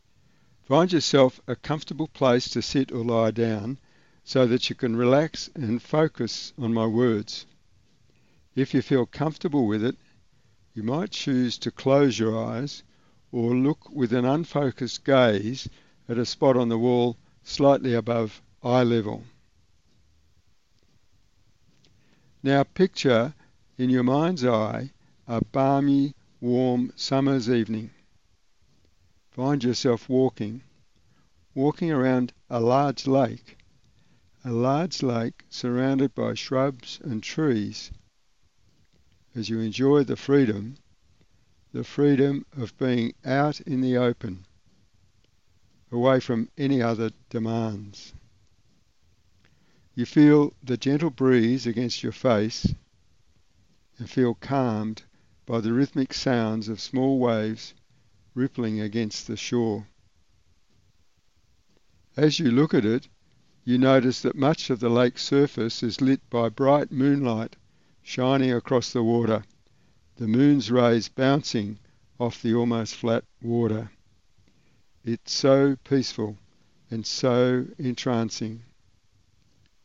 “Release Unwanted Emotions” Hypnosis Audio
This audio hypnosis session is designed to help you relax as it leads you into a light trance by focusing your mind on peaceful and relaxing images. As the audio progresses, you are gradually and gently introduced to positive suggestions that will help your unconscious mind let go of any emotions associated with the original triggering event, while leaving you with any lessons that the event might have been offering you.